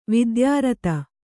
♪ vidyārata